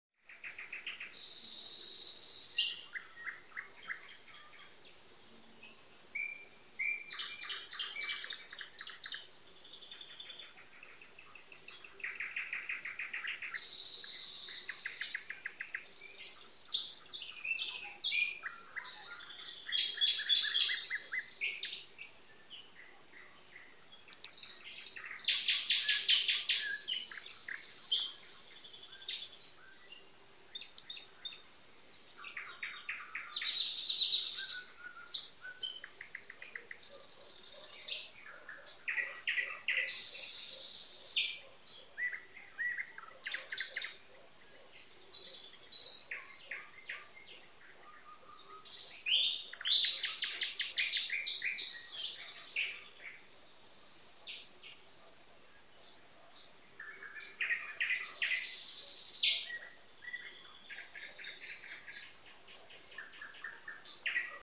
Nightingales Sound Button - Free Download & Play
Bird Sounds648 views